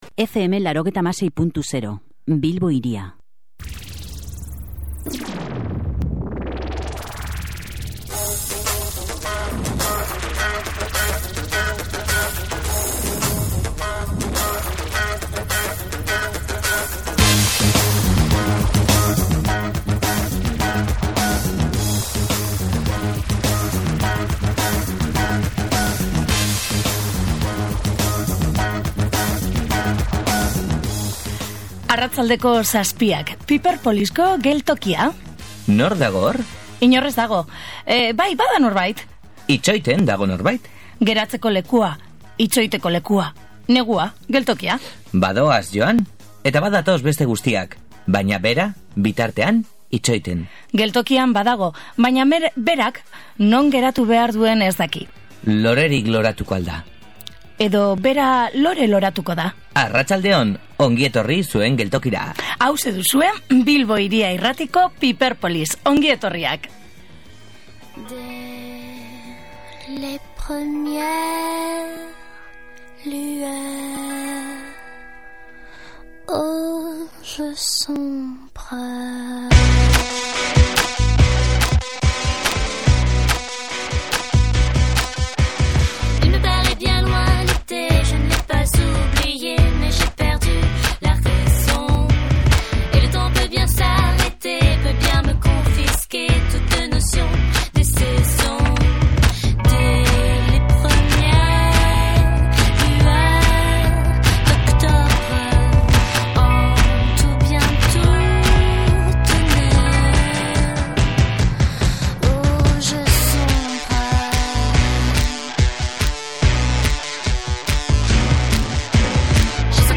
Gaurko saio honetan, konparazio baterako, Laura Mintegiri egindako elkarrizketa entzun dezakezu. Argitaratu berria duen Ecce Homo eleberriaz mintzatu zaigu Laura.
Beren eskutik, Kataluniako rumba zuzen-zuzenean entzuteko aukera dugu.